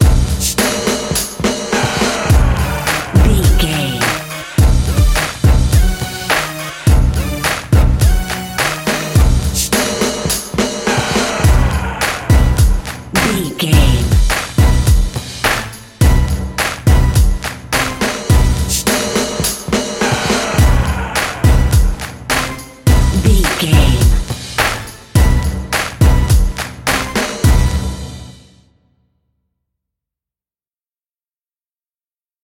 Aeolian/Minor
drum machine
synthesiser
hip hop
soul
Funk
confident
energetic
bouncy
funky